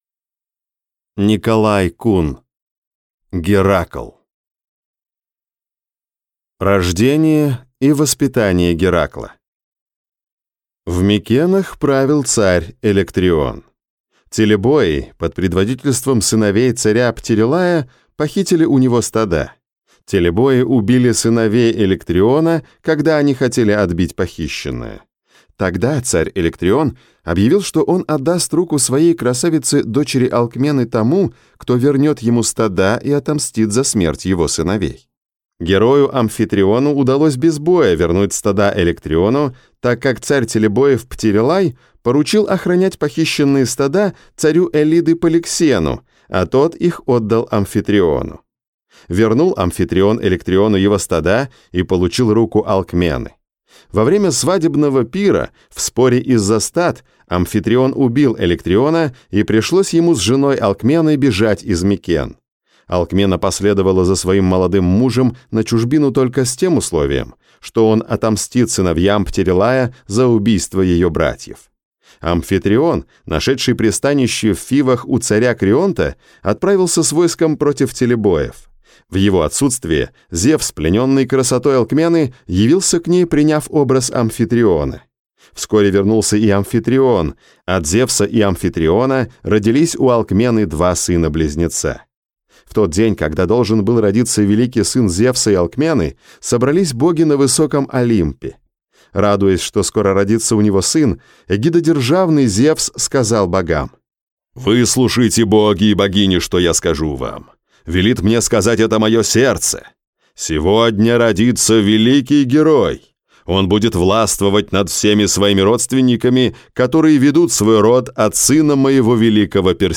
Аудиокнига Геракл | Библиотека аудиокниг